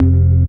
ORGAN-23.wav